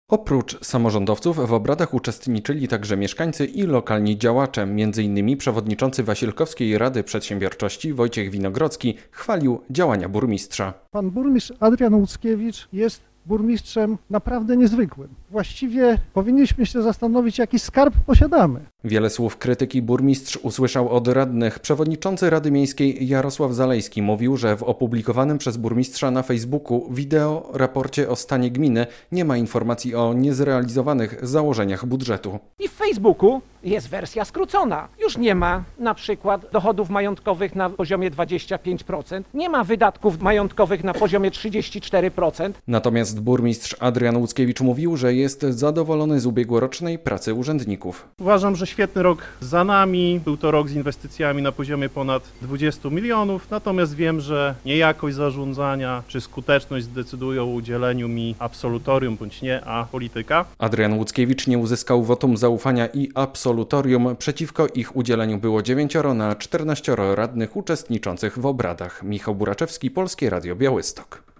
Burmistrz Wasilkowa bez absolutorium - relacja